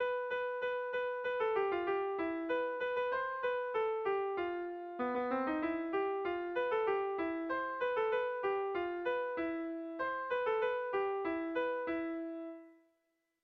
Bertso melodies - View details   To know more about this section
Gabonetakoa
AB